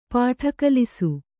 ♪ pāṭha kalisu